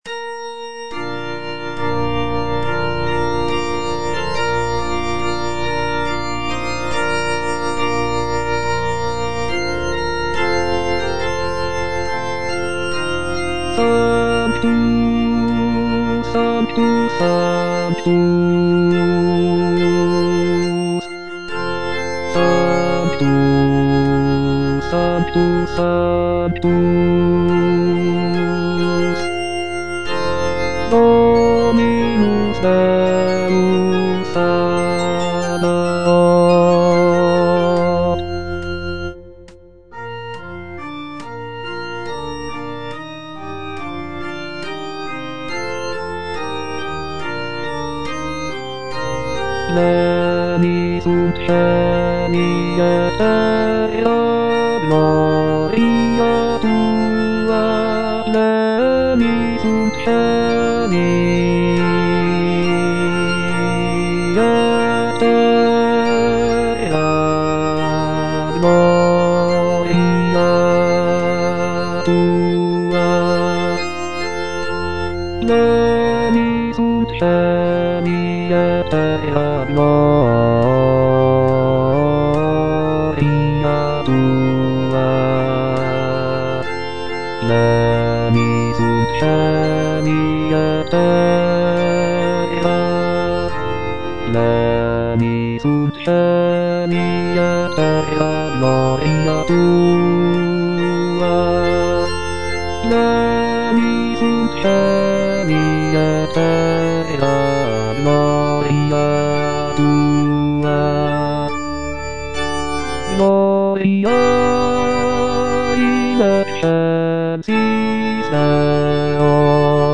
Bass (Voice with metronome) Ads stop
is a sacred choral work rooted in his Christian faith.